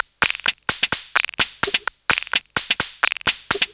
VEH1 Fx Loops 128 BPM
VEH1 FX Loop - 27.wav